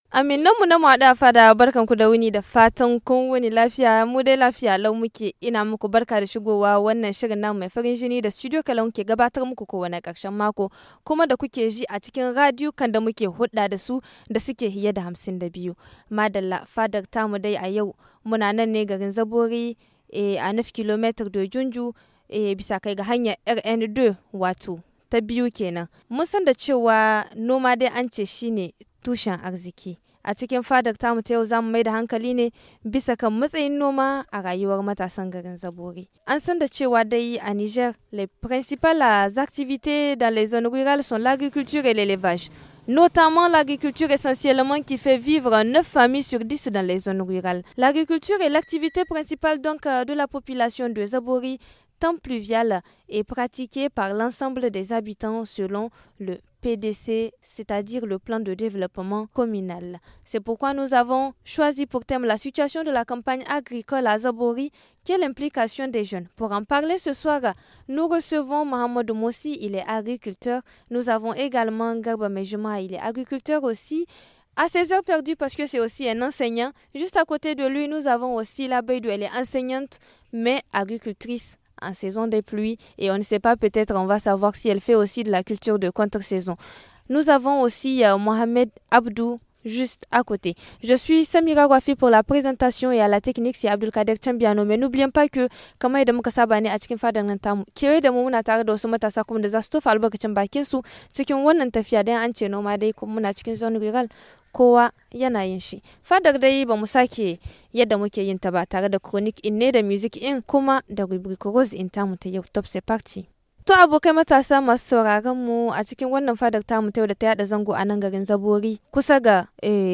La fada en franco-haoussa